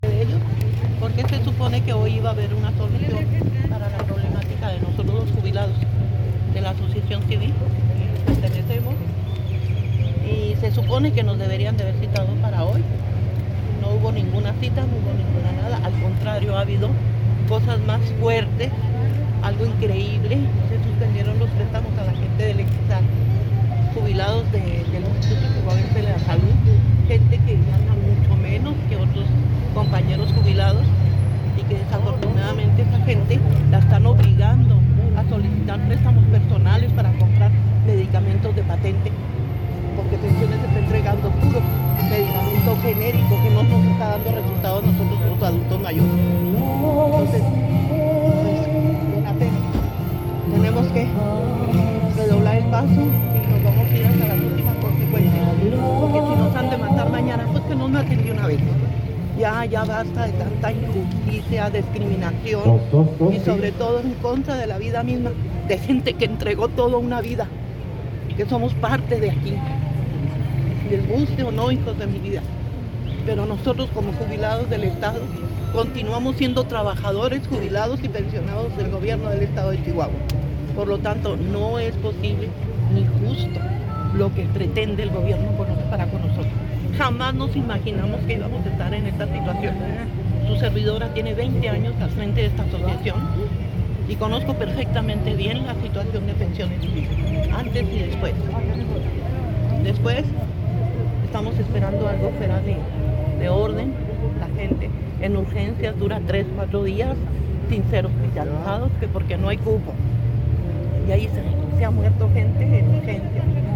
Un grupo de maestras y maestros jubilados se reunió esta mañana en la Plaza Hidalgo, frente a Palacio de Gobierno. Realizaron una protesta pacífica en contra de los medicamentos genéricos y la crisis en el servicio médico de Pensiones Civiles del Estado.